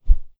Swing On Air
Close Combat Swing Sound 87.wav